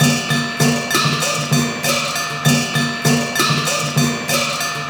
China Drums.wav